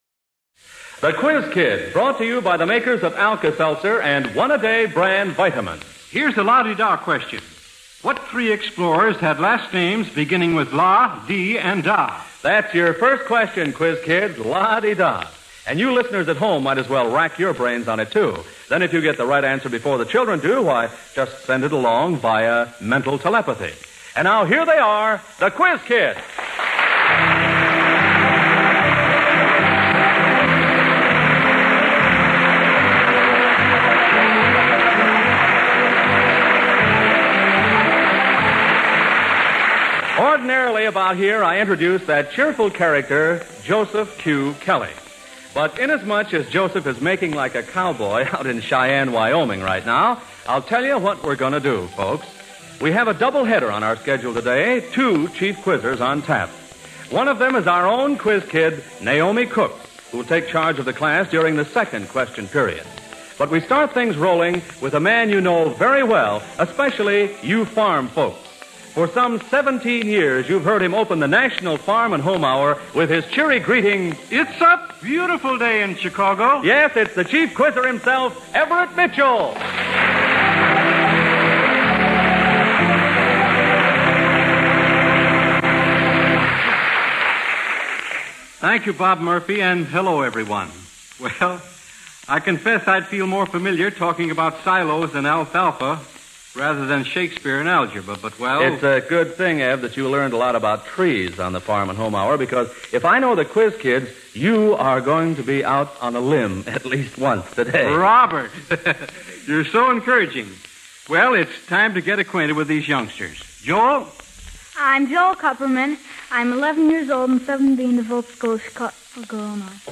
The Quiz Kids Radio Program